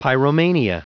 Prononciation du mot pyromania en anglais (fichier audio)
Prononciation du mot : pyromania